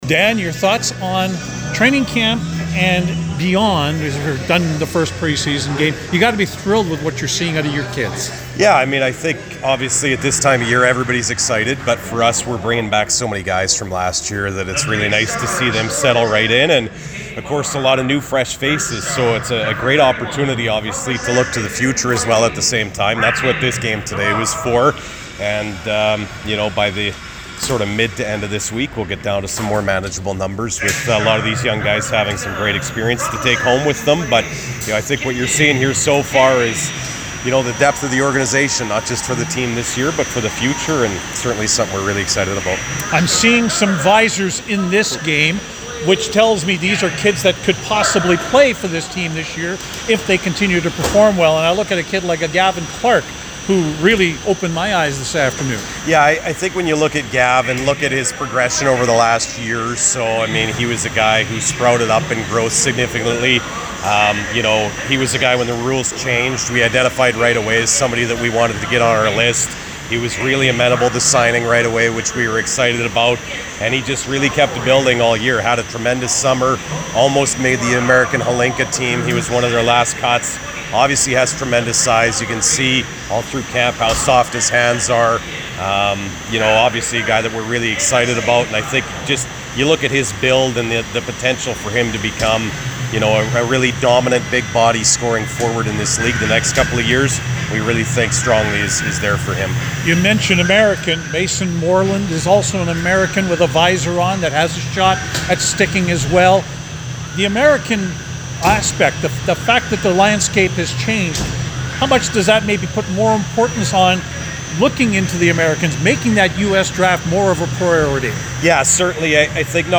post-game interviews